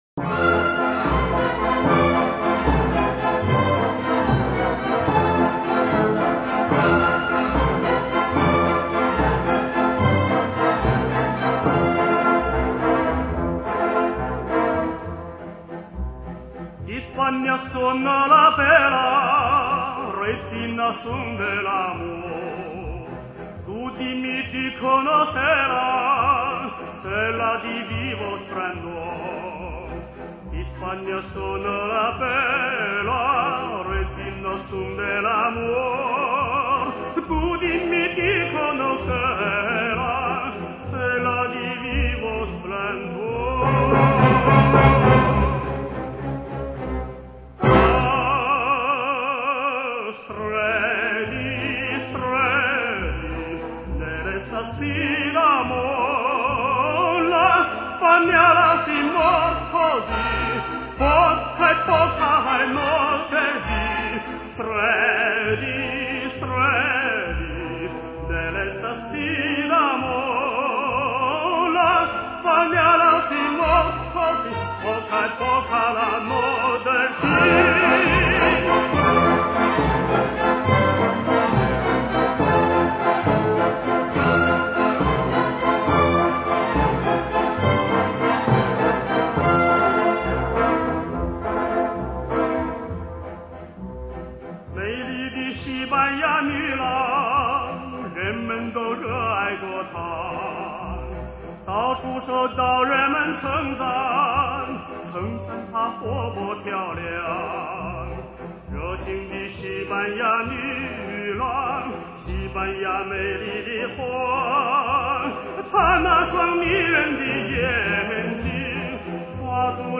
历历岁月，世纪回望，世界民歌，乐韵悠长。